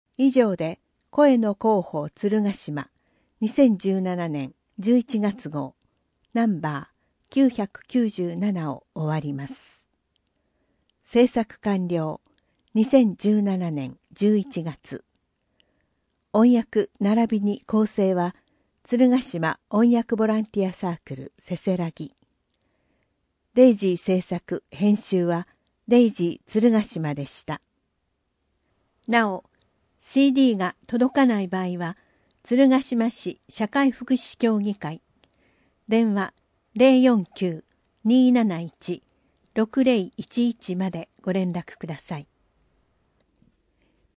声の広報つるがしまは、「鶴ヶ島音訳ボランティアサークルせせらぎ」の皆さんが「広報つるがしま」の内容を音訳し、「デイジー鶴ヶ島」の皆さんがデイジー版CDを製作して、目の不自由な方々へ配布をしています。